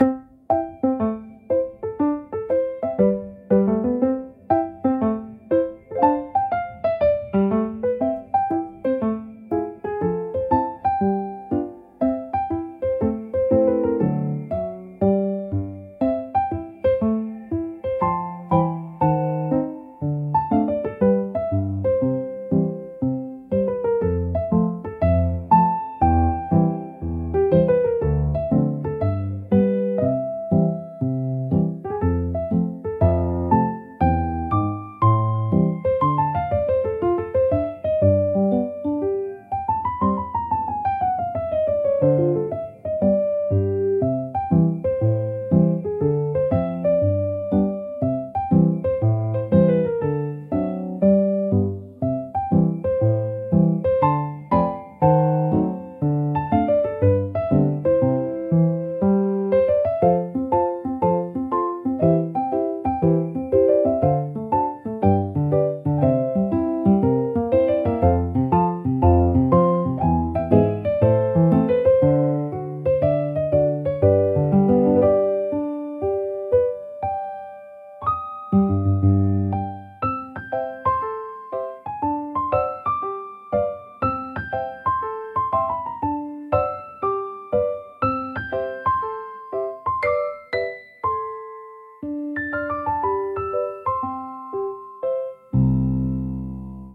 シンプルなメロディラインが心地よいリズムを生み、穏やかながらも前向きなムードを演出します。
ゆったりとした軽快なピアノソロが日常のさりげないシーンを優しく彩るジャンルです。
ピアノの柔らかなタッチが集中をサポートし、疲れを癒す効果を発揮します。